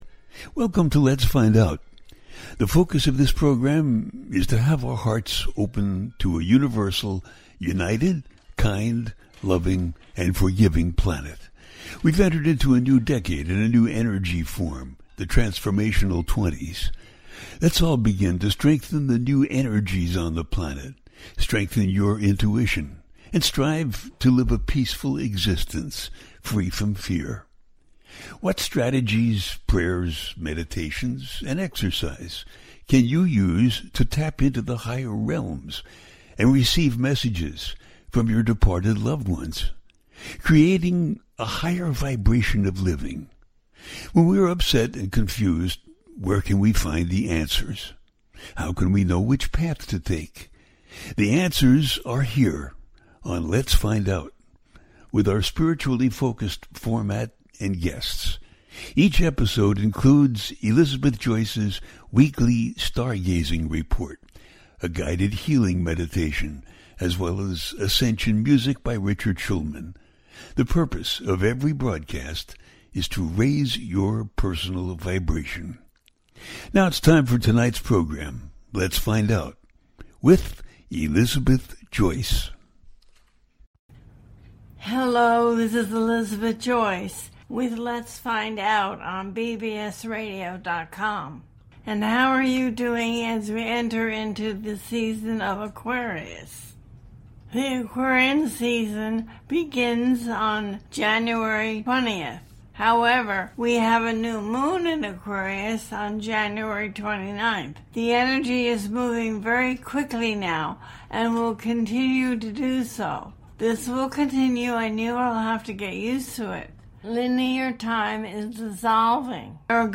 The New Moon In Aquarius 2025 - A teaching show